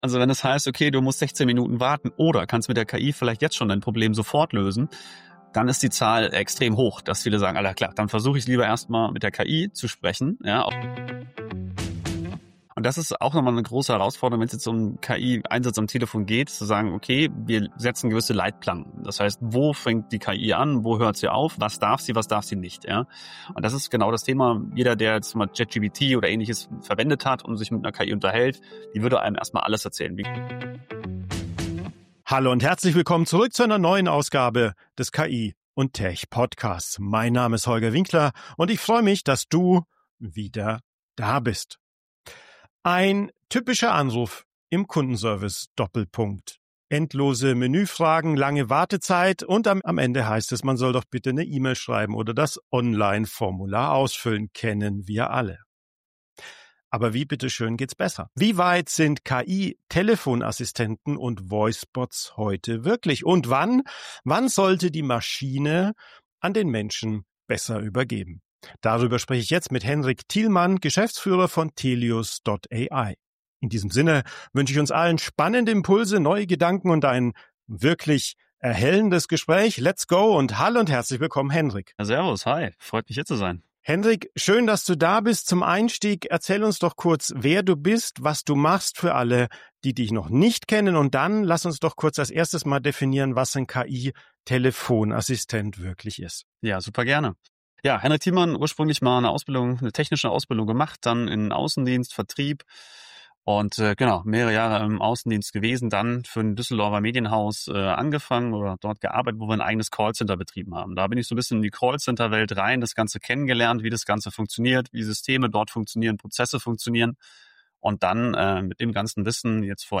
Im Fokus steht die zentrale Frage: Wann übernimmt die KI sinnvoll Aufgaben im Kundenservice – und wann braucht es zwingend den Menschen? Das Gespräch liefert konkrete Praxisbeispiele aus B2C- und B2B-Szenarien und zeigt, wie Unternehmen Effizienz steigern, Kosten senken und gleichzeitig die Customer Experience verbessern können. Warum sollten Sie dieses Interview nicht verpassen?